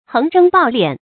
注音：ㄏㄥˋ ㄓㄥ ㄅㄠˋ ㄌㄧㄢˇ
橫征暴斂的讀法